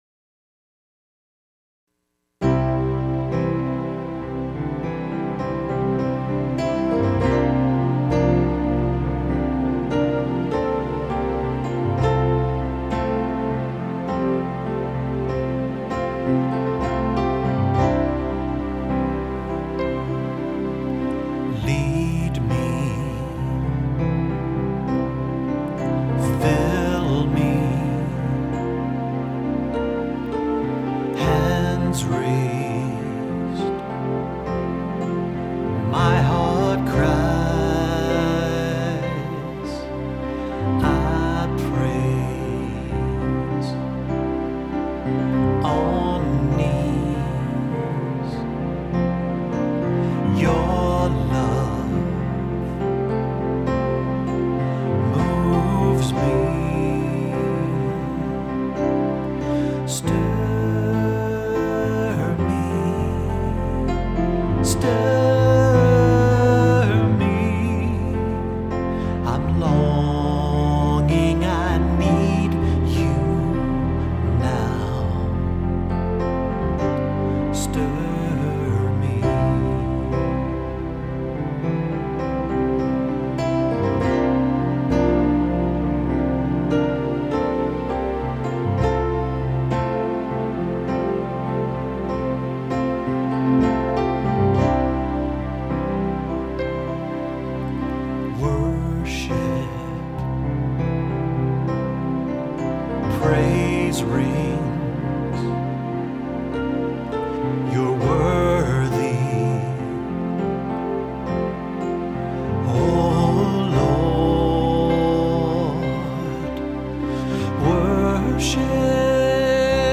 peaceful worship song